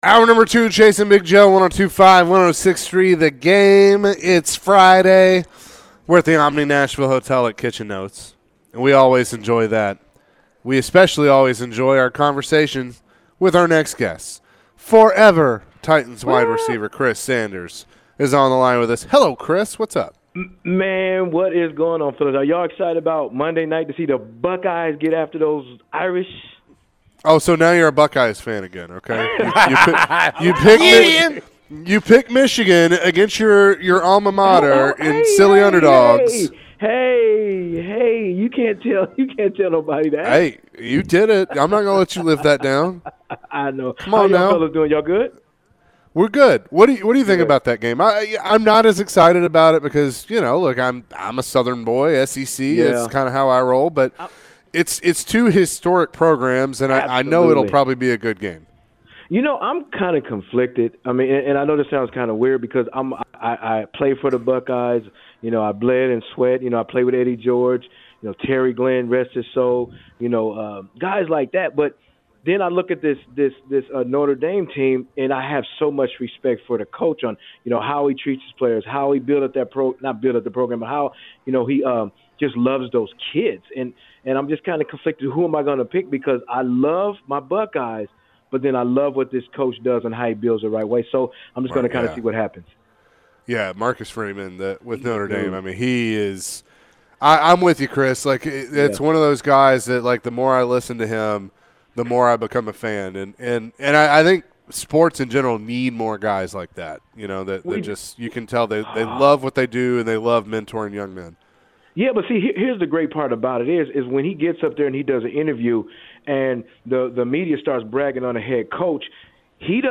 Forever Titans WR Chris Sanders joined the show and shared his thoughts on the National Championship game taking place Monday night. Chris also mentioned some comments surrounding the Tennessee Titans.